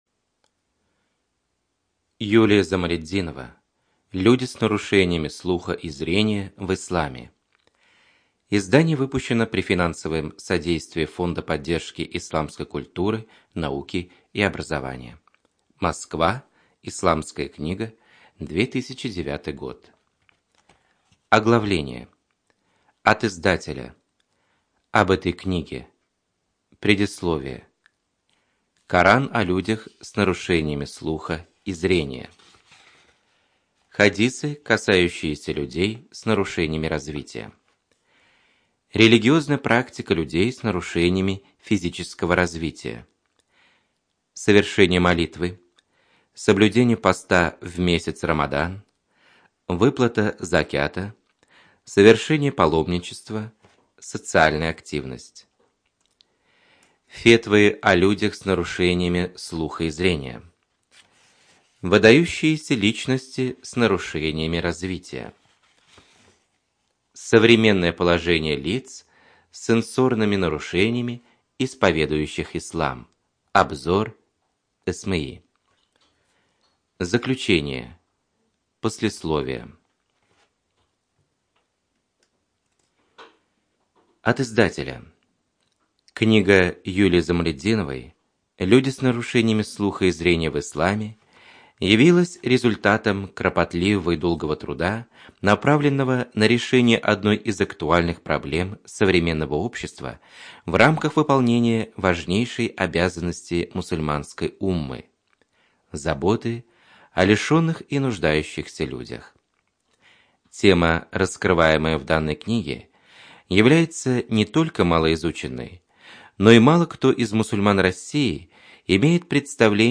Студия звукозаписиТатарская республиканская специальная библиотека для слепых и слабовидящих